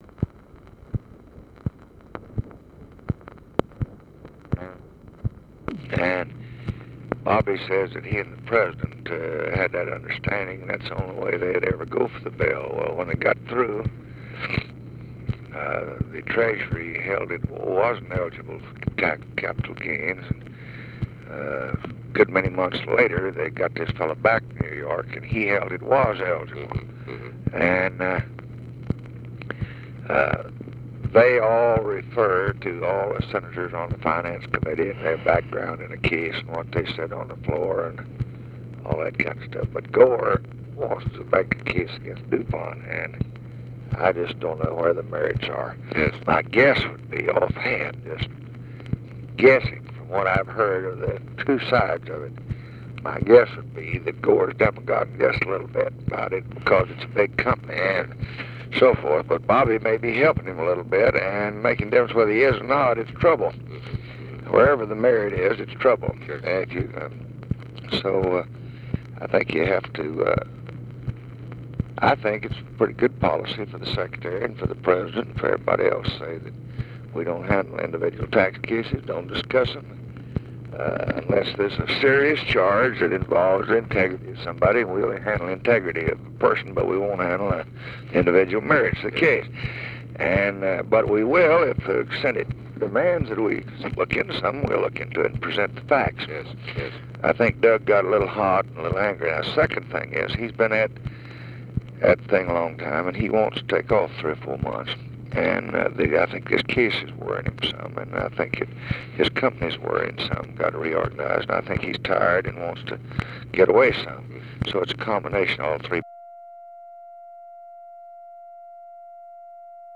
Conversation with KERMIT GORDON, March 15, 1965
Secret White House Tapes